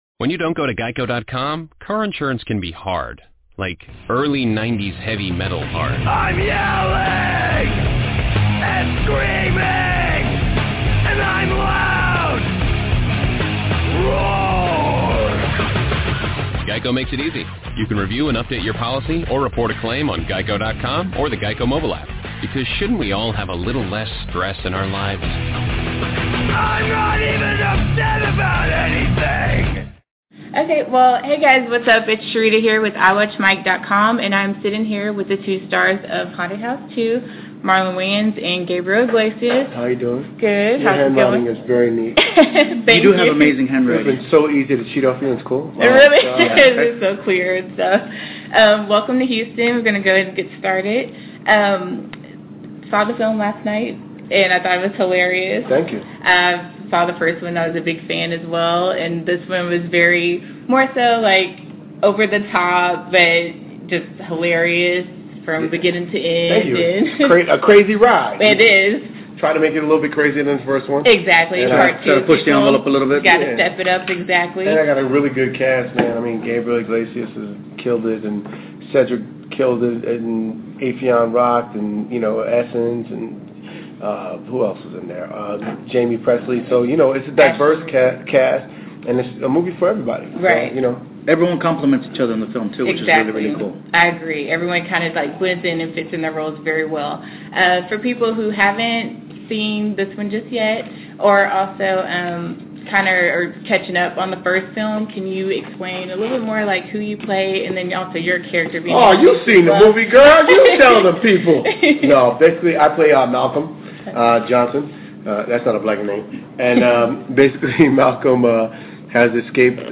A Haunted House 2 – Marlon Wayans & Gabriel Iglesias – Interview